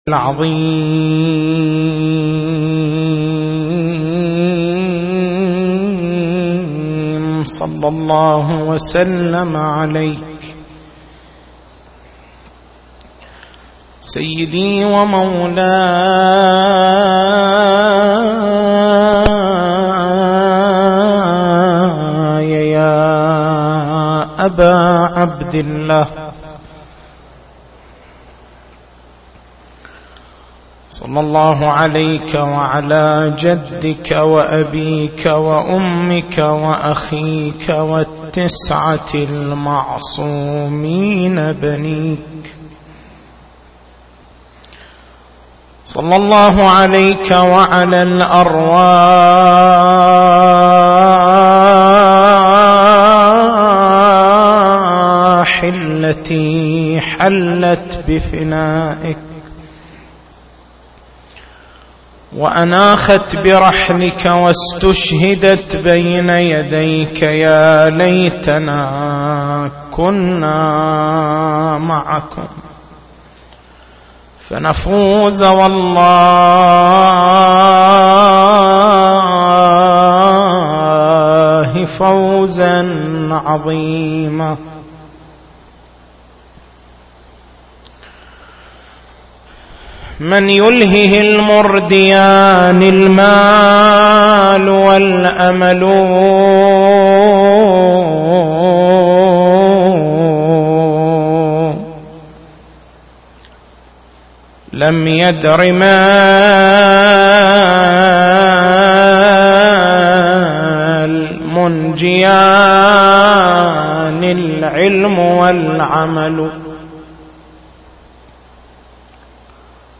شبكة الضياء > مكتبة المحاضرات > شهر رمضان المبارك > شهر رمضان المبارك 1433